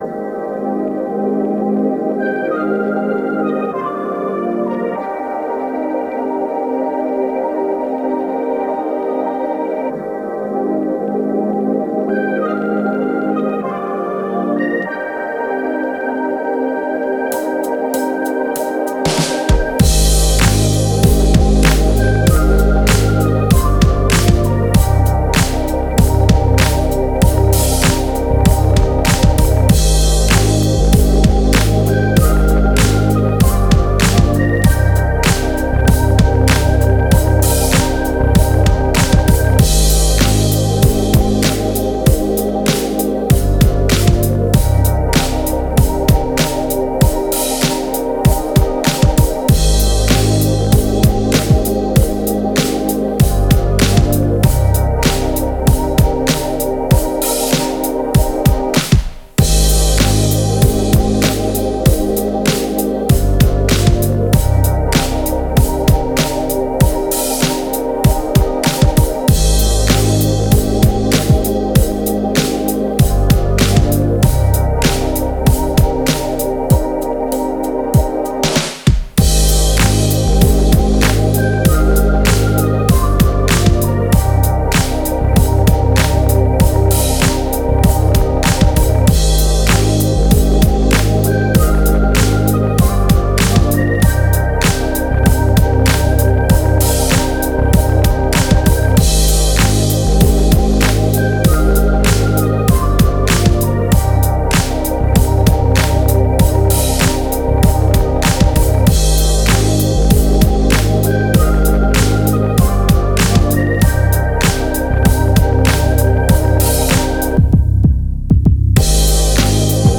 BPM96
NOTABb
MOODEmocional
GÉNEROHip-Hop